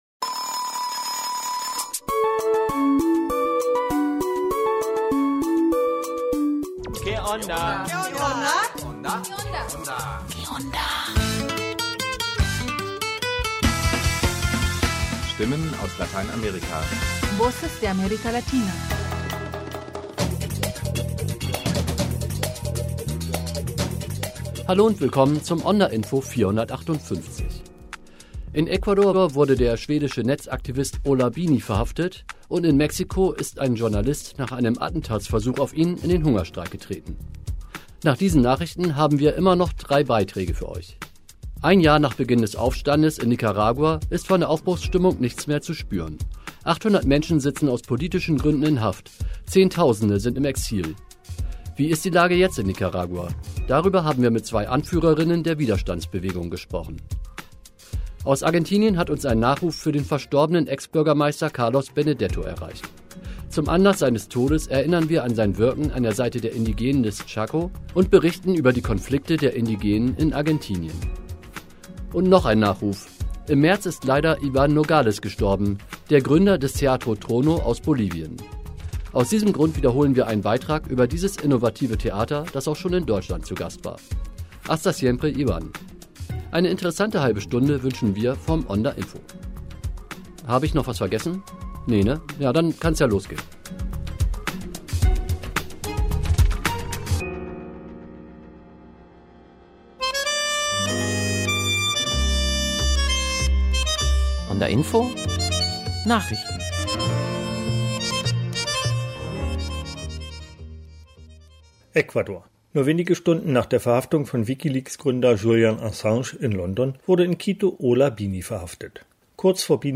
Sendezeit: Alle 2 Wochen am Donnerstag von 18.30 - 19 Uhr (im Wechsel mit focus international). onda, das sind Reportagen, Magazinsendungen und Features über alles, was die lateinamerikanische Welt bewegt: indigene Rechte und Frauenmorde in Mexiko ebenso wie queerer Cumbia aus Argentinien oder Ökotourismus in Costa Rica.